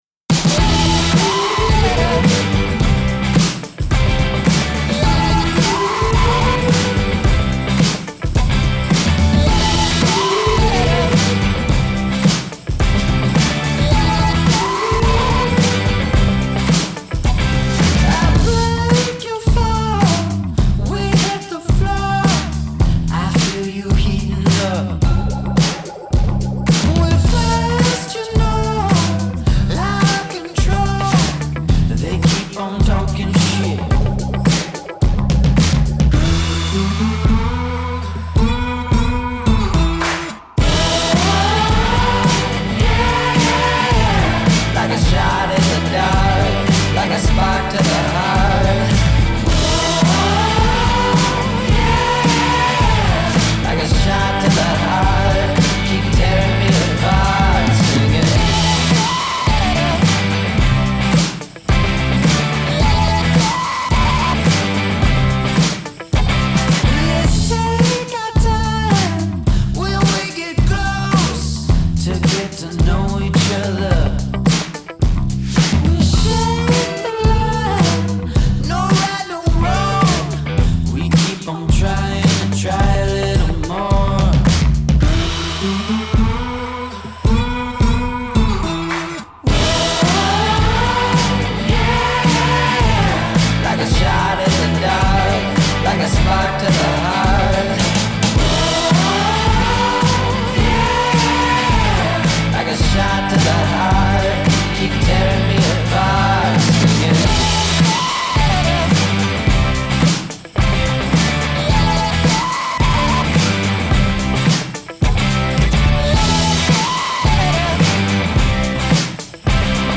Sun-soaked feel-good songs to let loose by.